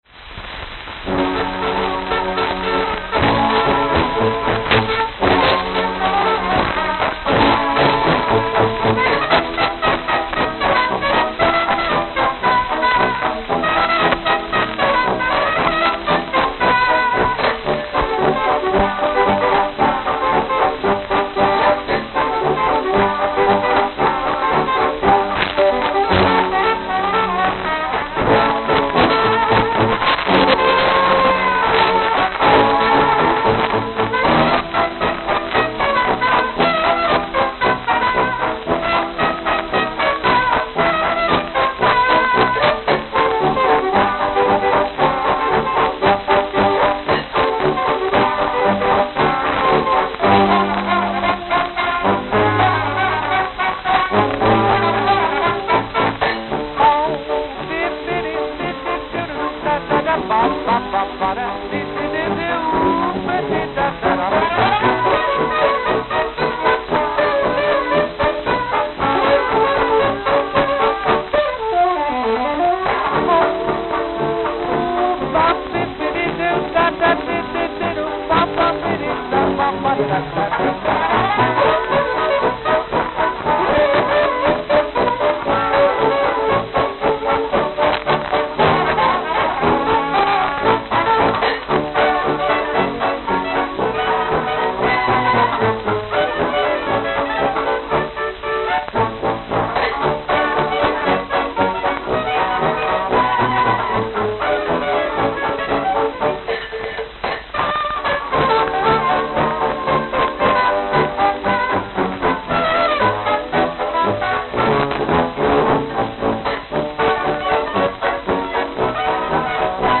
Note: Extremely worn.